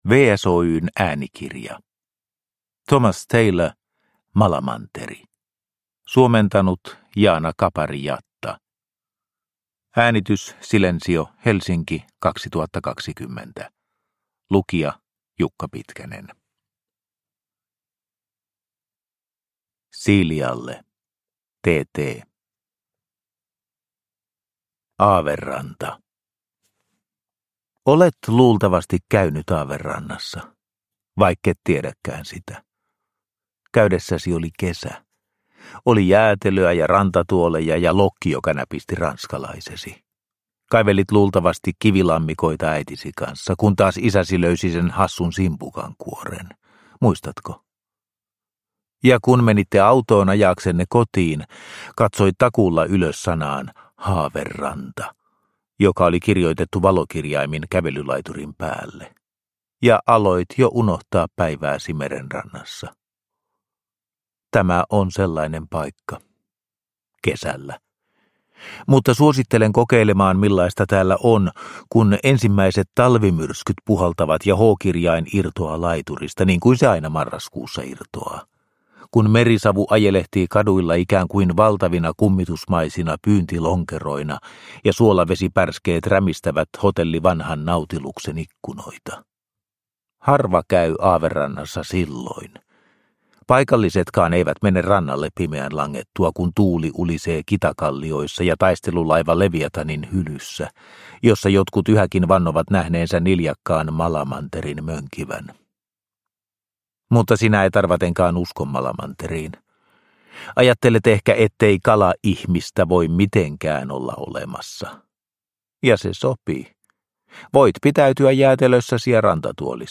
Malamanteri – Ljudbok – Laddas ner